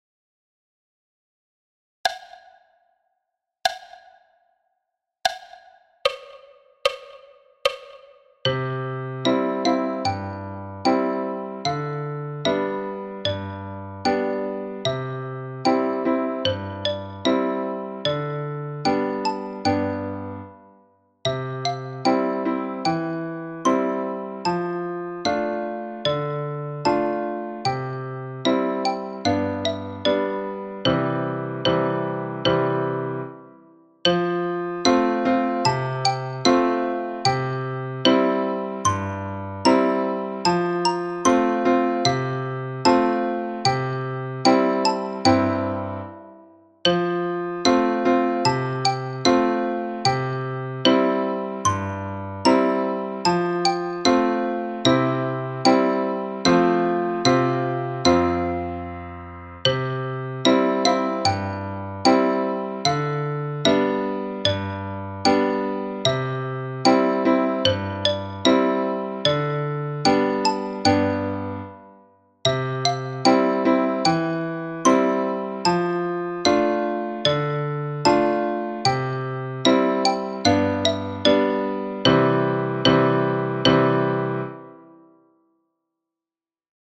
Danse du canard – tutti à 75 bpm